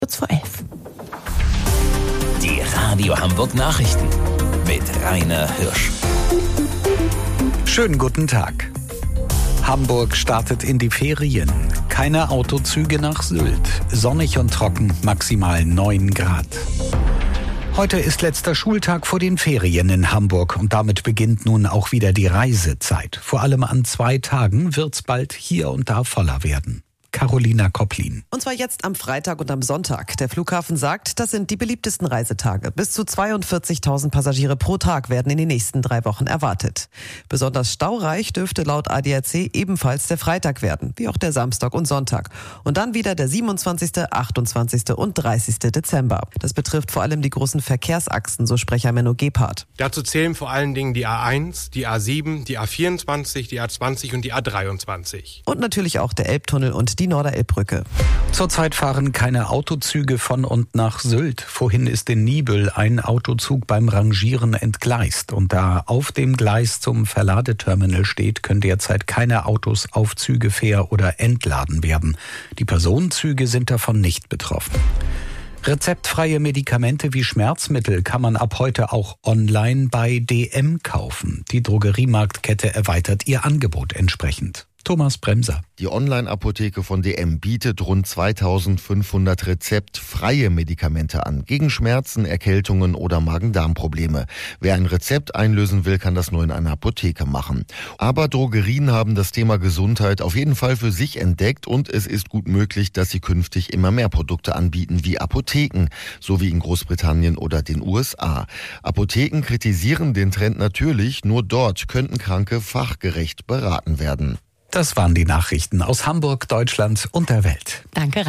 Radio Hamburg Nachrichten vom 16.12.2025 um 11 Uhr